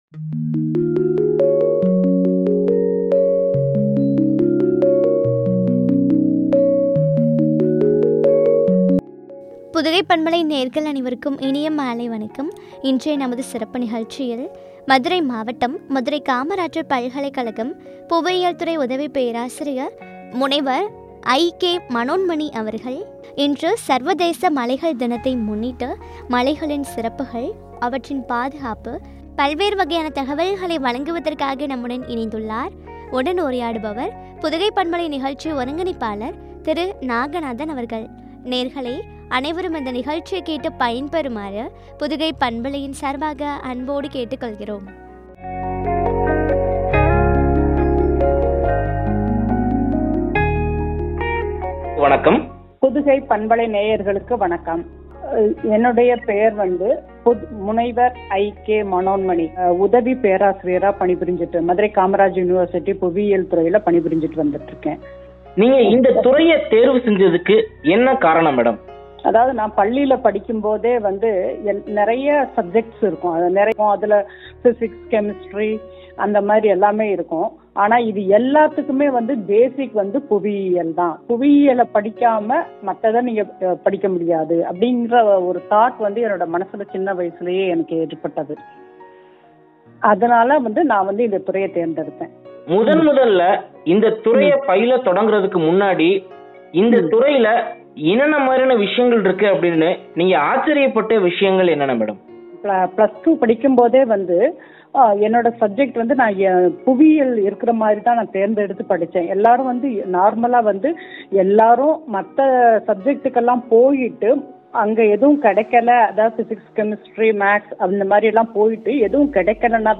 இயற்கையின் கொடை குறித்து வழங்கிய உரையாடல்.